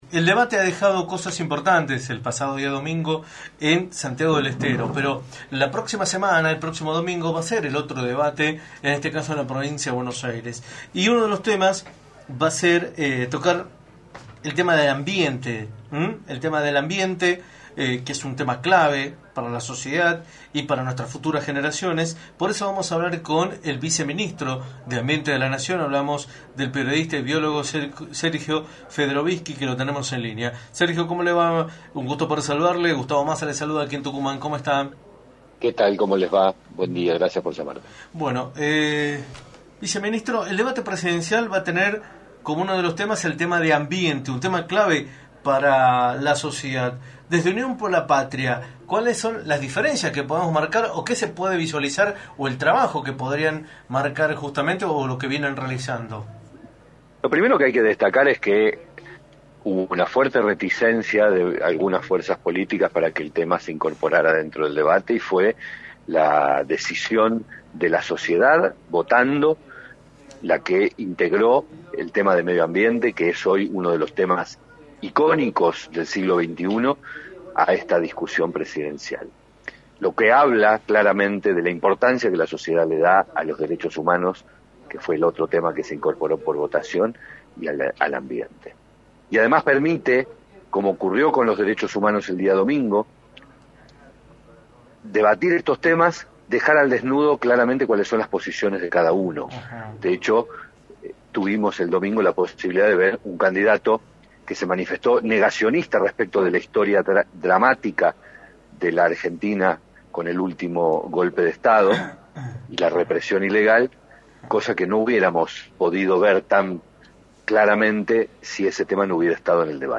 Sergio Federovisky, Viceministro de Ambiente de la Nación, indicó en Radio del Plata Tucumán, por la 93.9, cuáles son las propuestas que diferencian a Unión por la Patria de sus adversarios en materia de medio ambiente.
“Debatir estos temas permite dejar al desnudo cual es la postura de cada uno de los candidatos, porque por un lado tenemos a Sergio Massa y sus propuestas y a eso hay que contrastarlo con el negacionismo de Javier Milei, el cual realiza afirmaciones absurdas como que el cambio climático no existe y por otro lado con Patricia Bullrich, la cual nunca abordó este tema” señaló Sergio Federovisky en entrevista para “La Mañana del Plata”, por la 93.9.